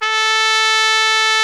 Index of /90_sSampleCDs/Club-50 - Foundations Roland/BRS_xTrumpets 1/BRS_xTrumpets 1